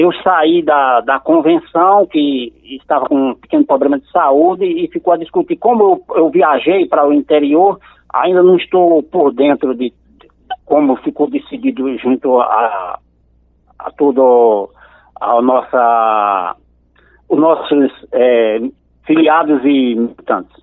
durante entrevista ao programa Arapuan Verdade, da Rádio Arapuan FM, que ainda não sabe quem será o seu candidato a vice-governador.